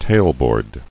[tail·board]